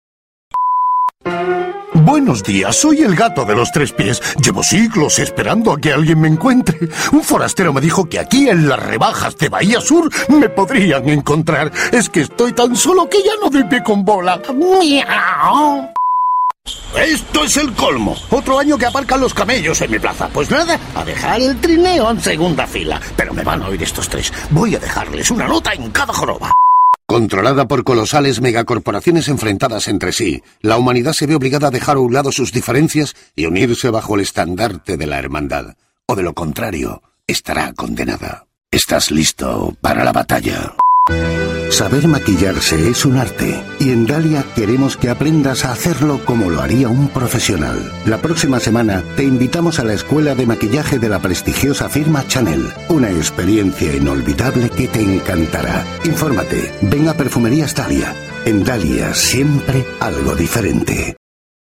Grave, expresiva, de tono profundo, impactante, enérgica y convincente.
Sprechprobe: Industrie (Muttersprache):